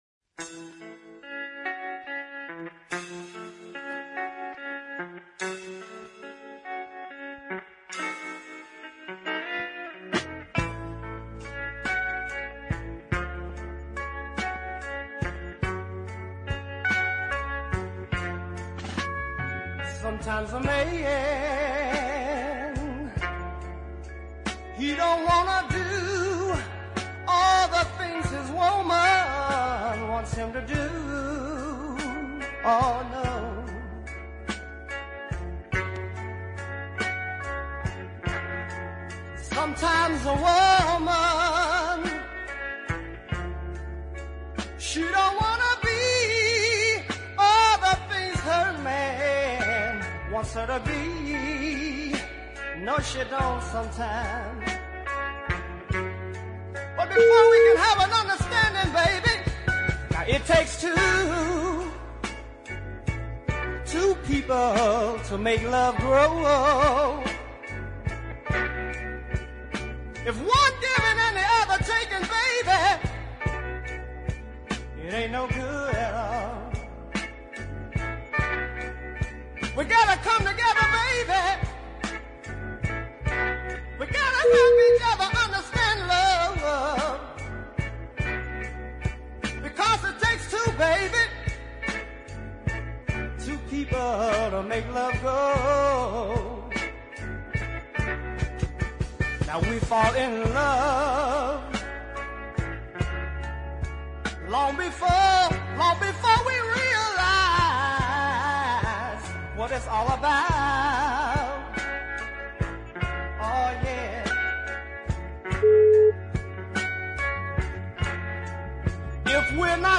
small band ballads of both power and presence
But the super lead vocal is excellent on both the tracks.